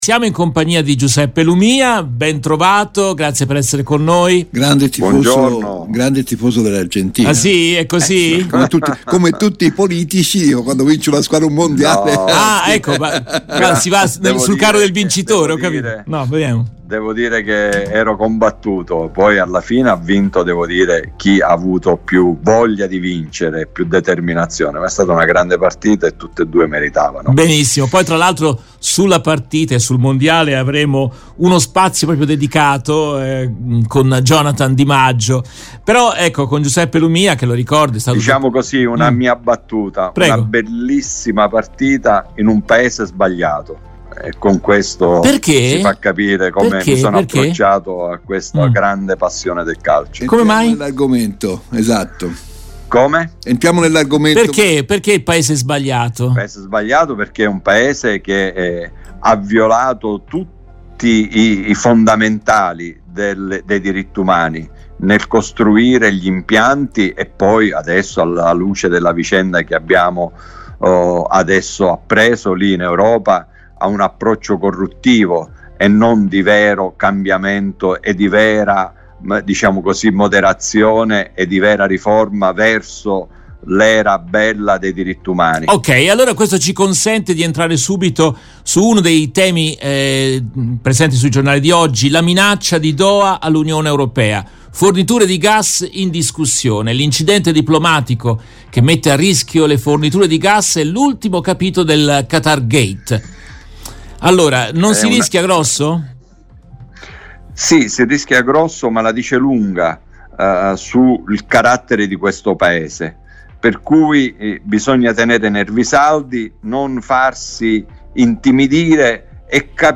In questa trasmissione in diretta del 9 dicembre 2022